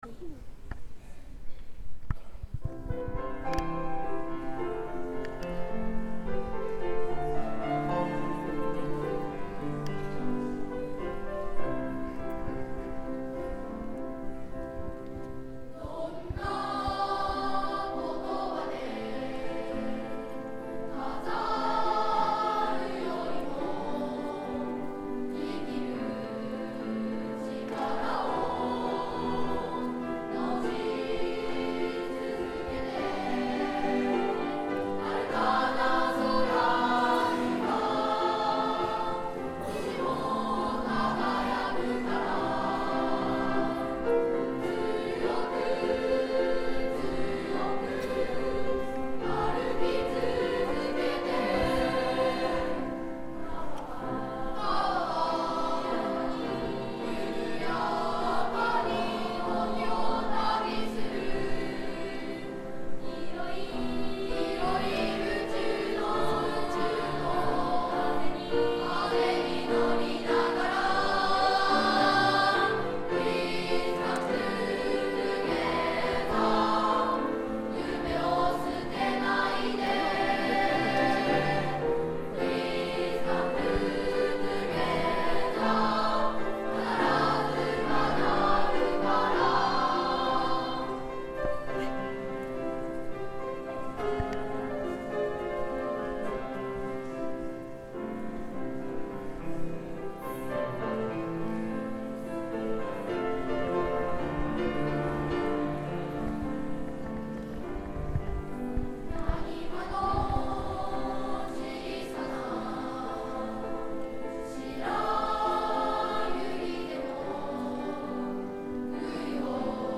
１Ｅ この星に生まれて.mp3 ←クリックすると合唱が聴けます